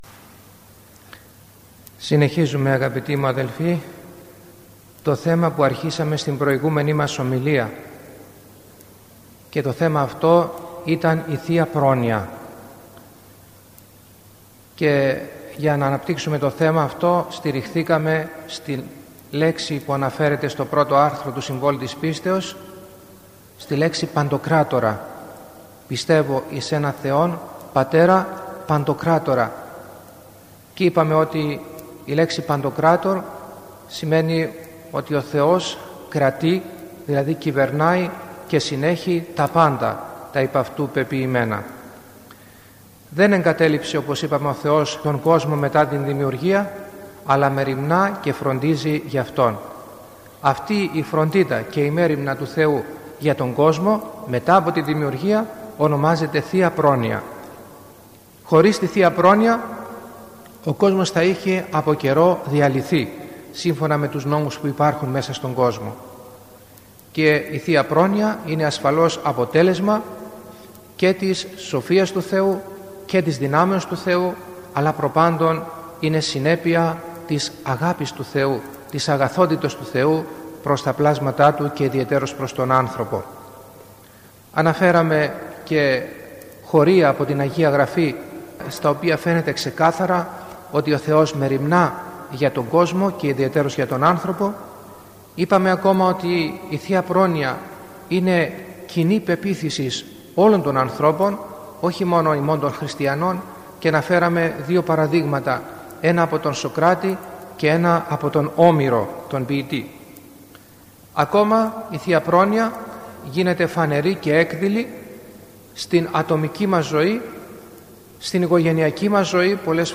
Η παρούσα ομιλία έχει θεματολογία «Περί θείας Προνοίας (Β’)».